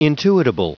Prononciation du mot intuitable en anglais (fichier audio)